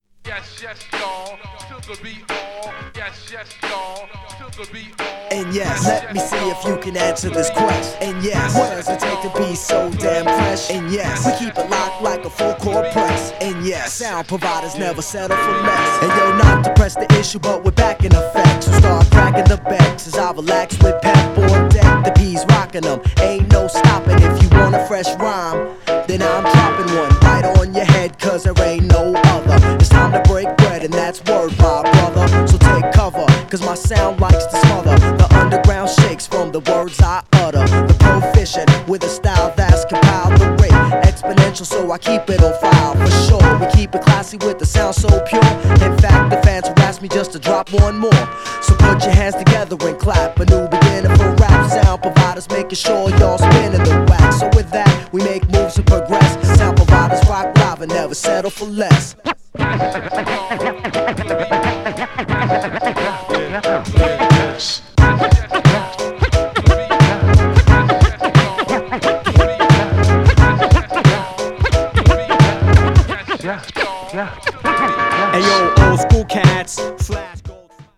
SWINGY & JAZZYなB面も激プッシュ!!
GENRE Hip Hop
BPM 86〜90BPM